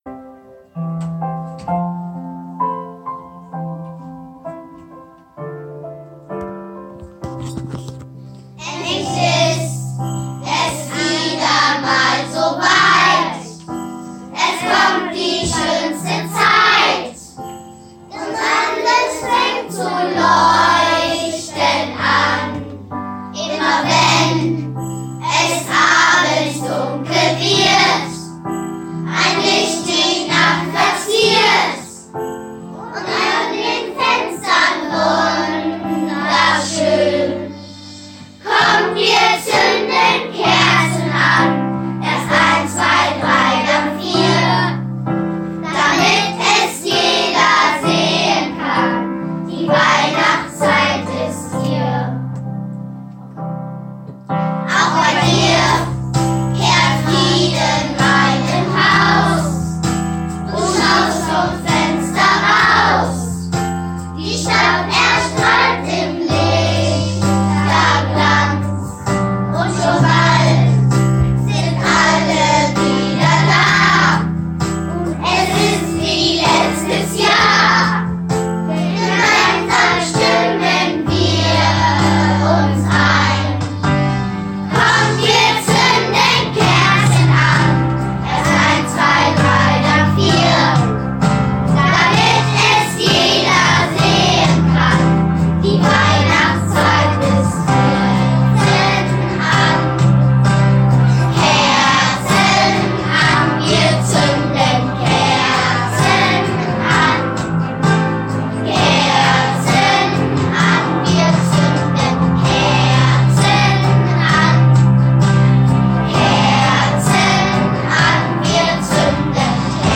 Chor_Kerzen_an.mp3